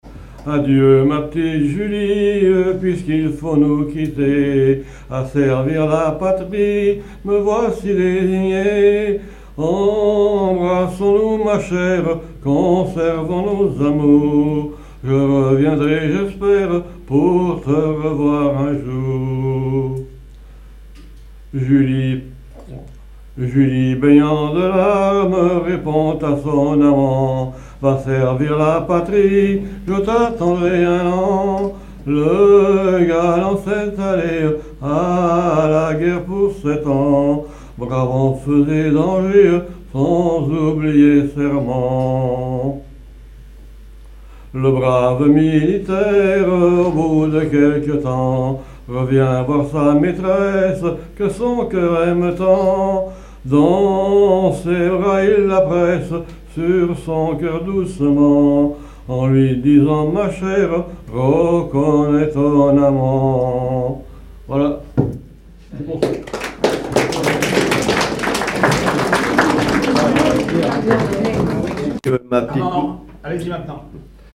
Genre strophique
Veillée (version Revox)
Pièce musicale inédite